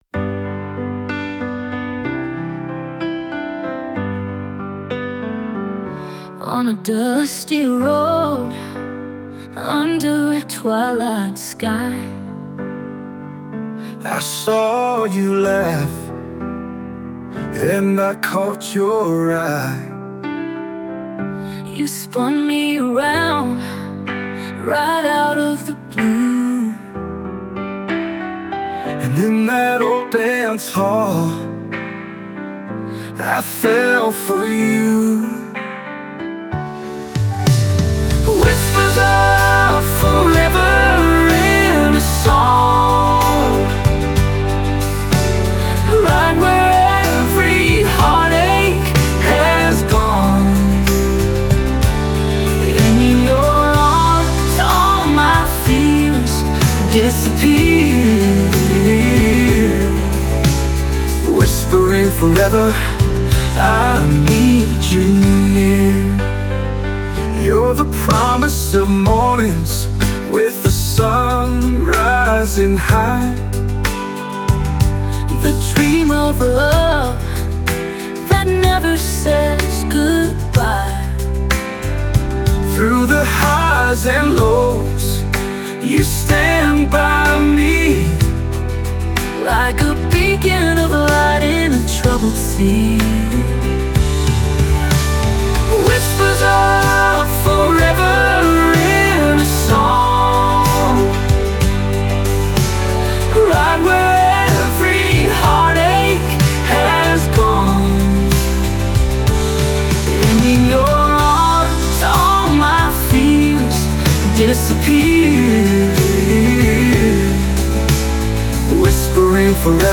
"Whispers of Forever" (country)